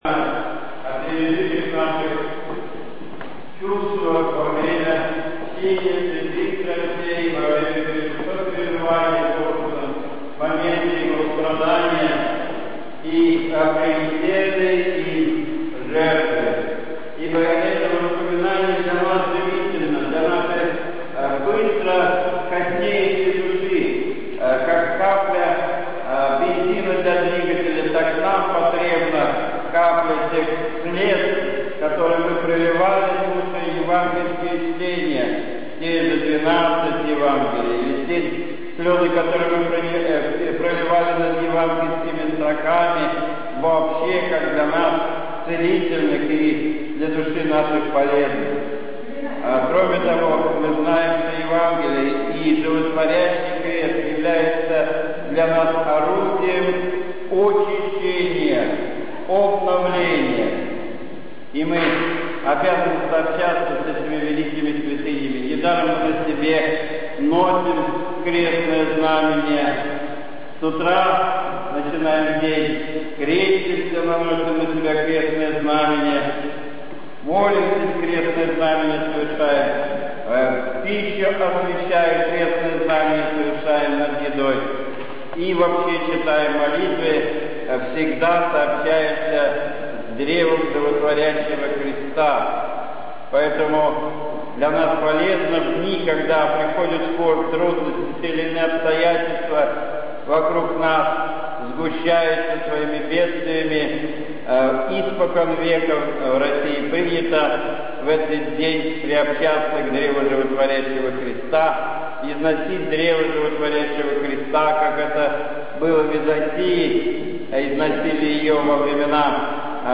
Божественная Литургия 14 августа 2009 года
Медовый Спасв храме Покрова Божией Матери